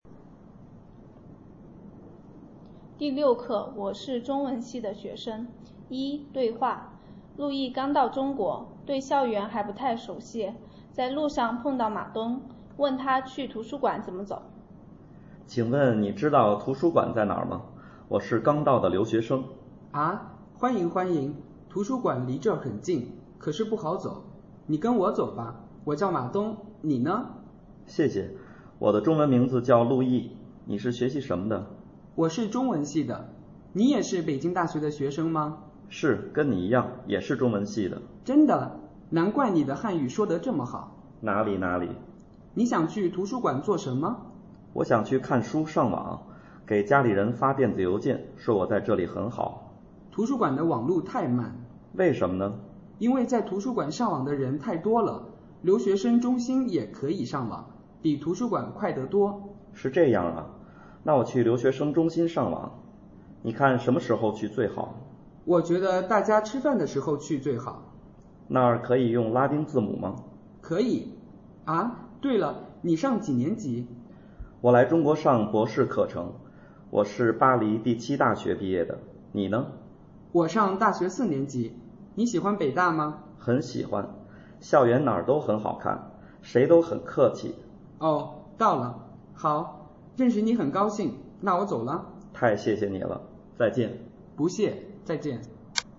Lengua China para traductores - Diálogo Lección 6